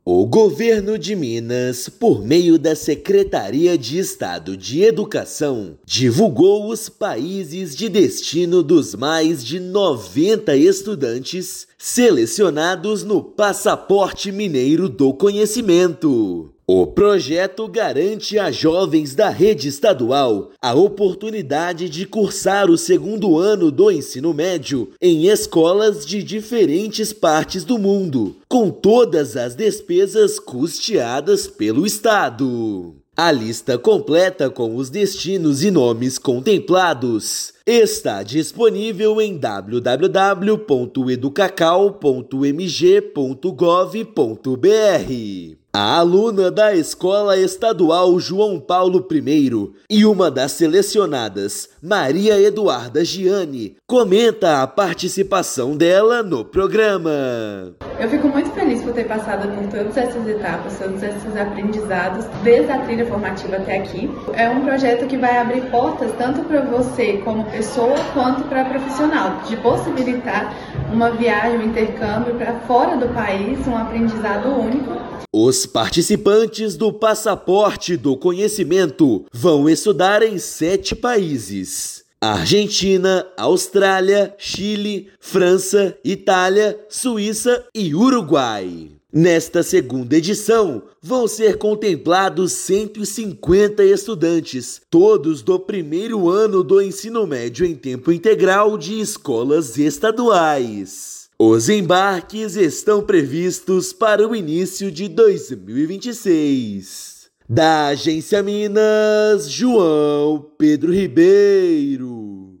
[RÁDIO] Governo do Estado divulga lista de países de destino dos estudantes do Passaporte Mineiro do Conhecimento
Intercâmbio gratuito levará jovens mineiros para três continentes a partir de 2026. Ouça matéria de rádio.